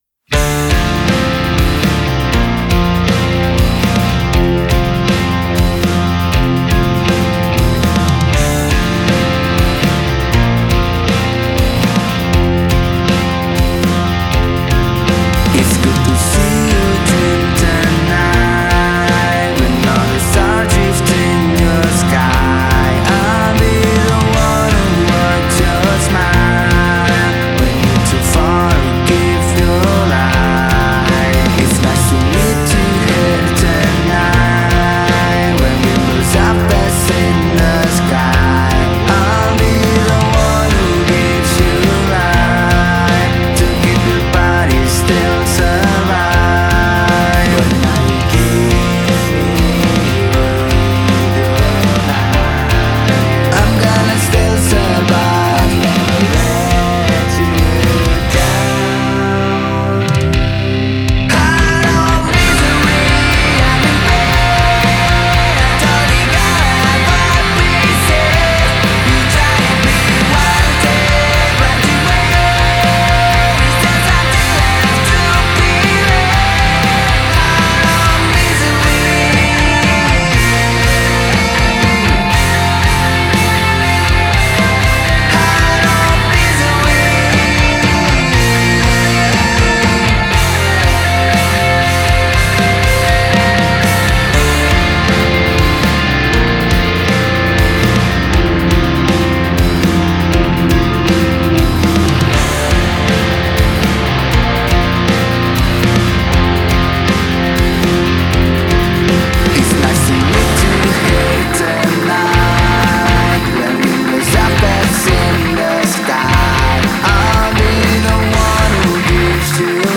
pop punk • Wonosobo